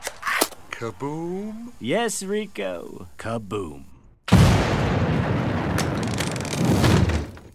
kaboom yes rico kaboom Meme Sound Effect
Category: Movie Soundboard